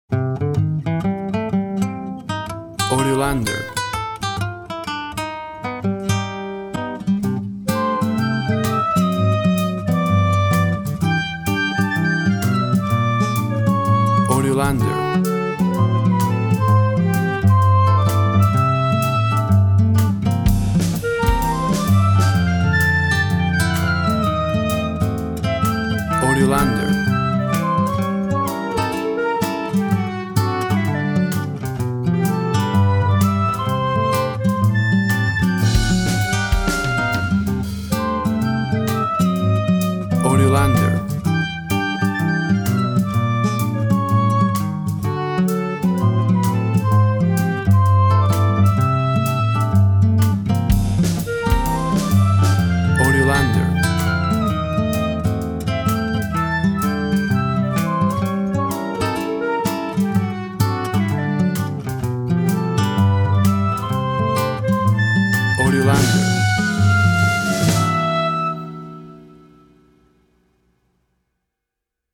WAV Sample Rate 24-Bit Stereo, 44.1 kHz
Tempo (BPM) 127